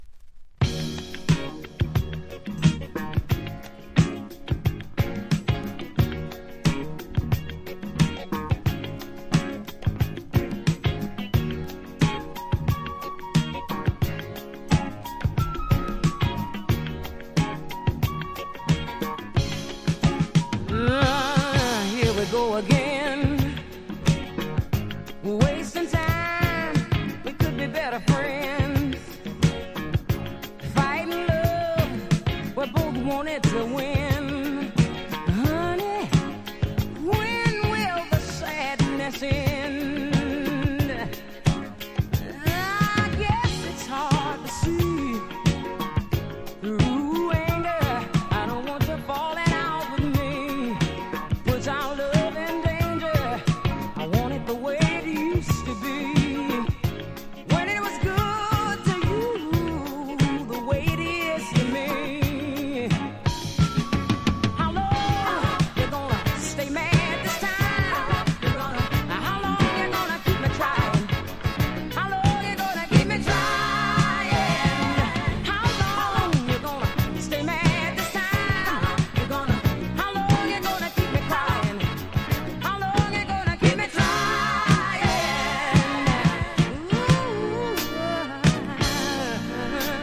1. REGGAE >